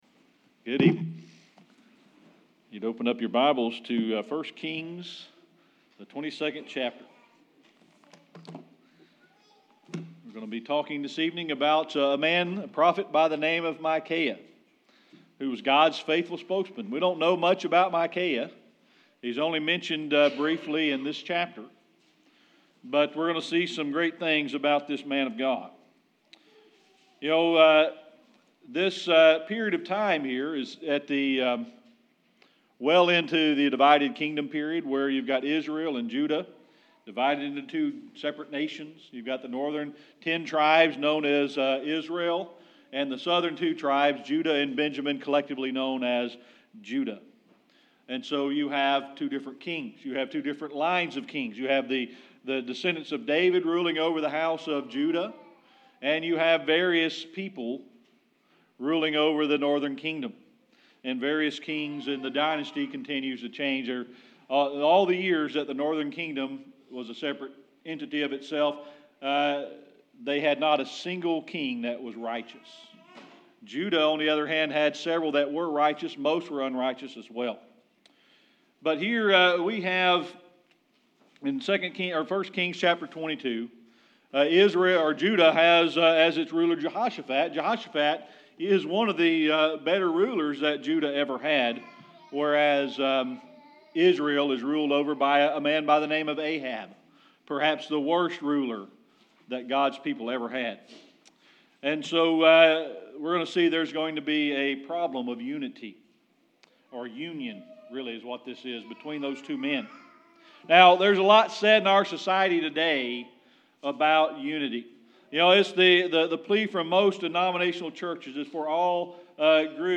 Series: Sermon Archives
1 Kings 22 Service Type: Sunday Evening Worship We're going to be talking tonight about God's faithful servant